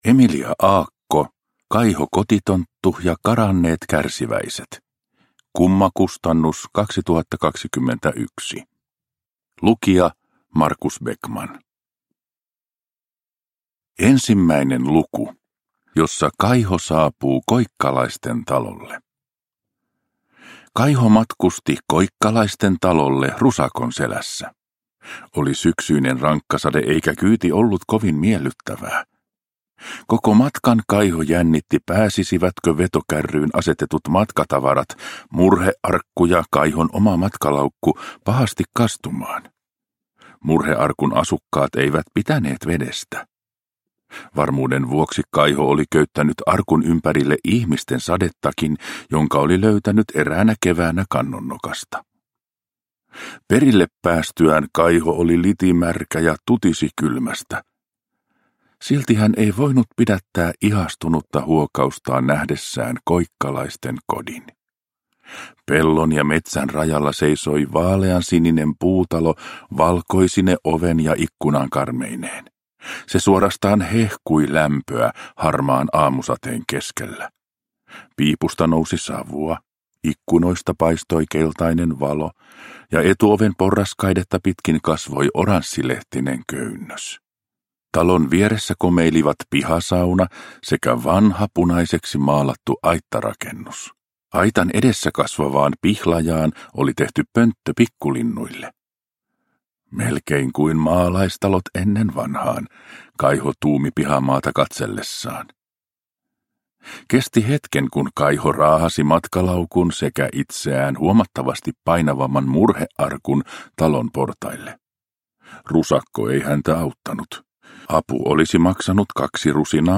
Kaiho-kotitonttu ja karanneet kärsiväiset – Ljudbok